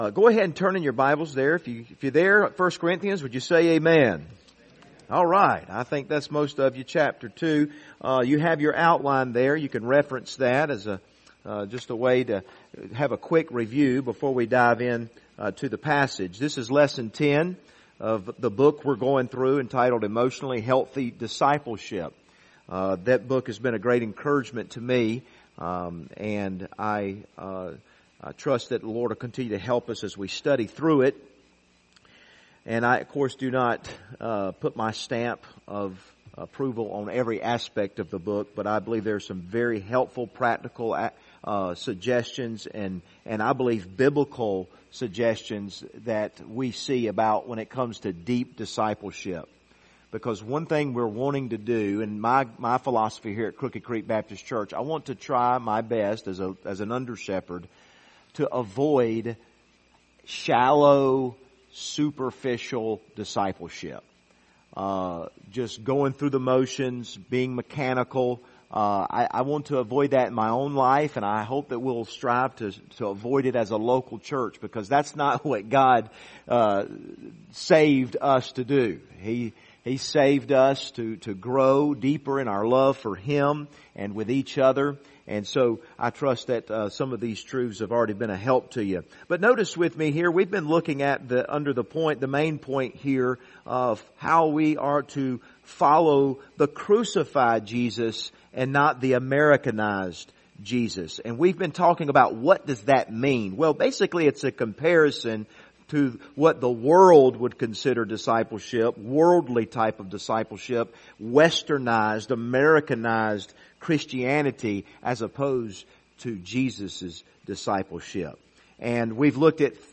Passage: 1 Corinthians 2 Service Type: Wednesday Evening